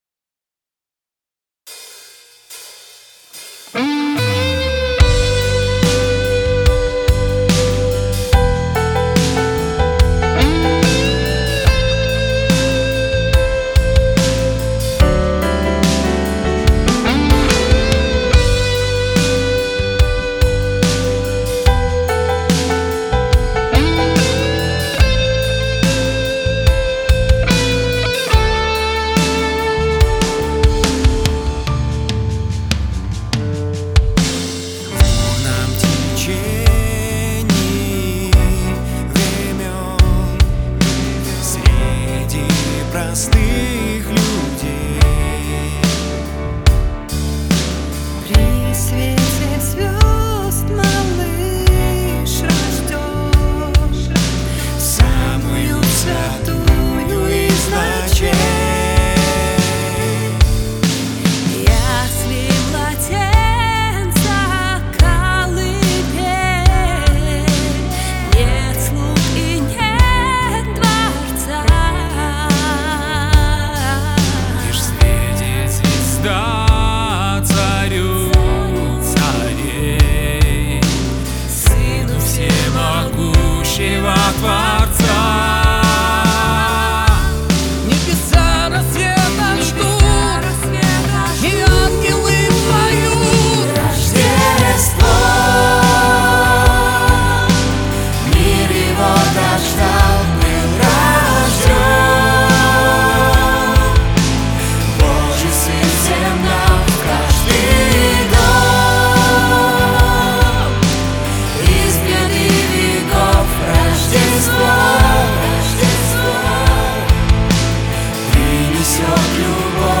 166 просмотров 434 прослушивания 15 скачиваний BPM: 72